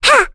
Reina-Vox_Attack1.wav